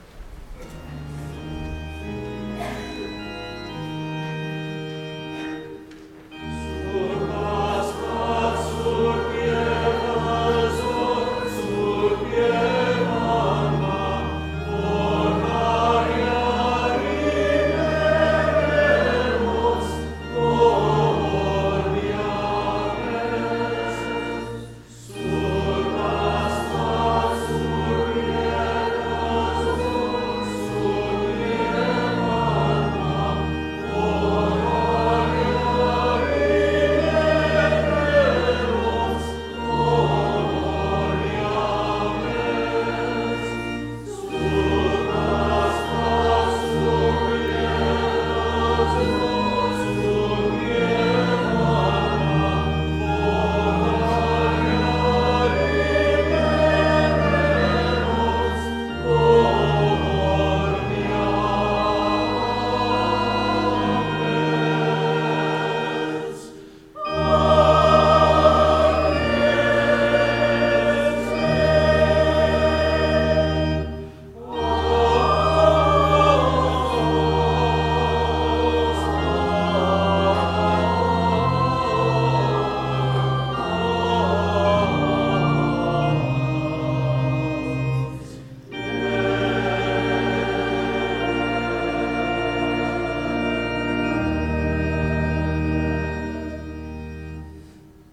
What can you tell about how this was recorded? April 12, 2020 -Easter Sunday